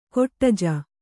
♪ koṭṭaje